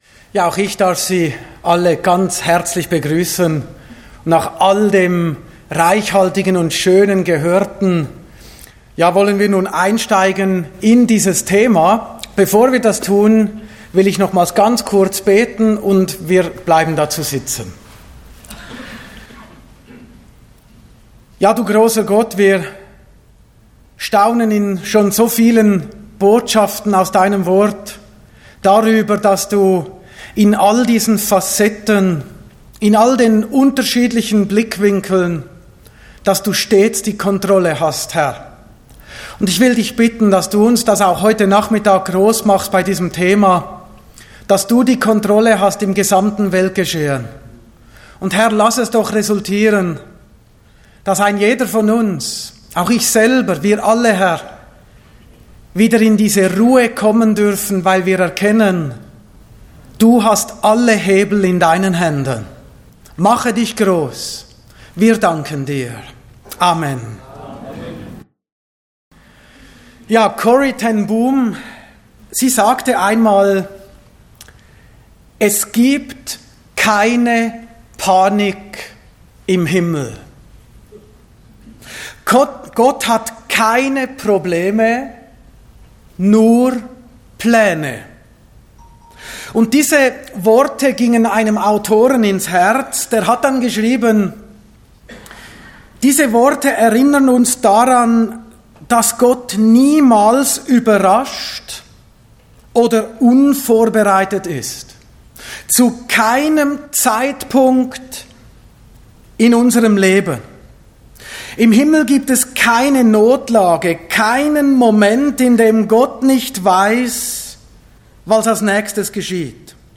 Botschaft Zionshalle https